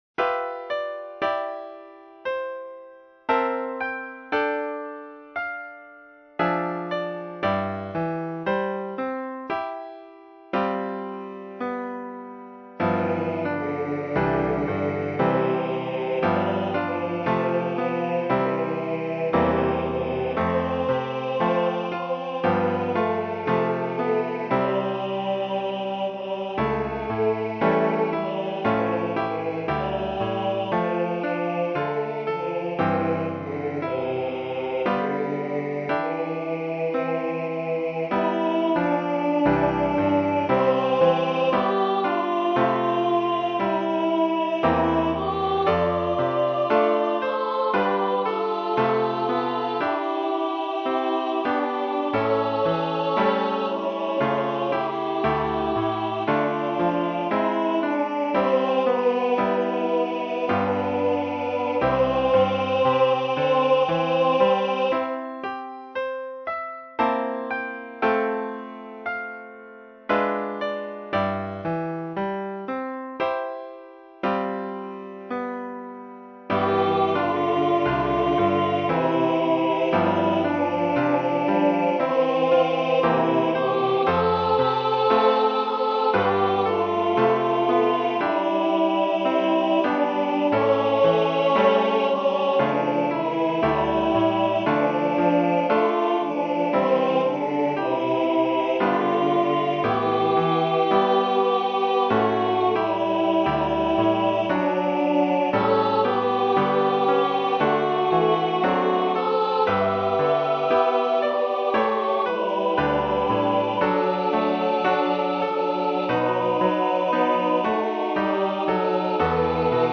(SAB)